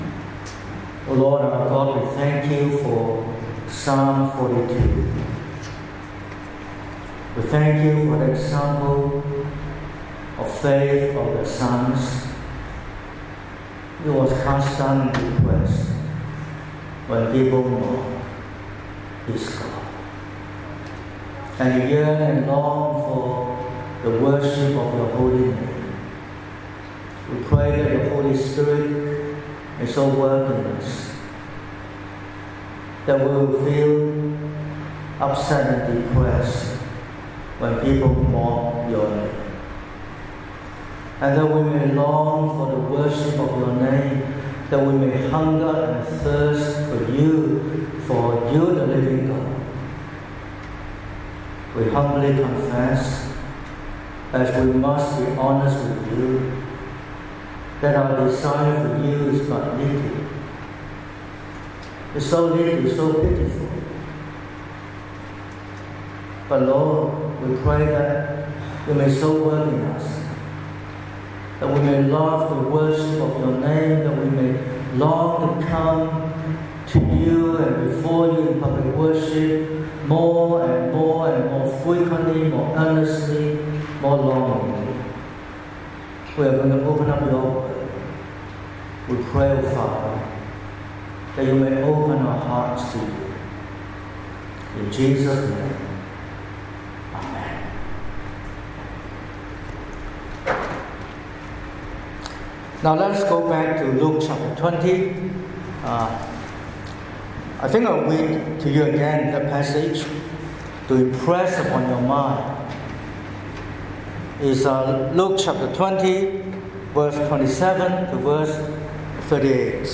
22/03/2026 – Morning Service: Marriage and the resurrection